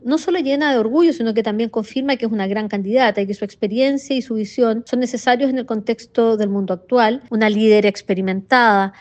Desde Nueva York, la embajadora de Chile en la ONU, Paula Narváez, destacó los resultados del sondeo y valoró la posición de la exmandataria.